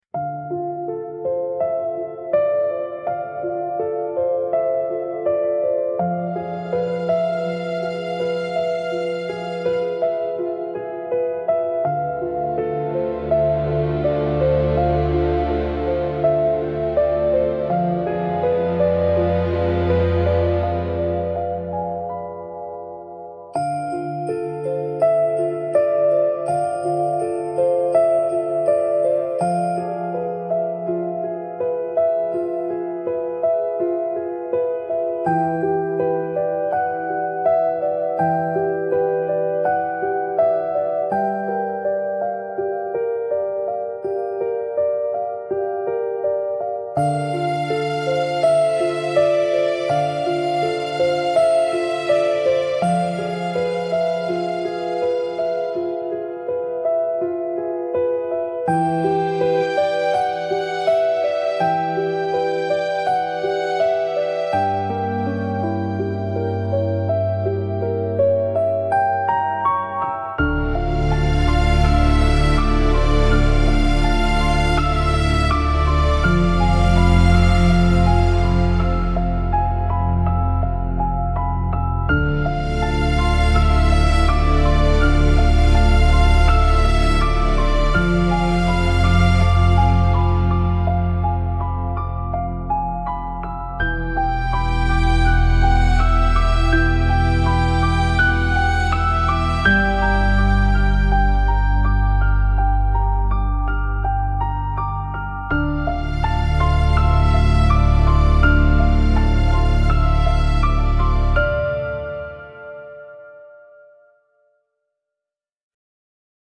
Cinematic Mystery Music With Emotional And Sad Melody
Genres: Background Music
Tempo: medium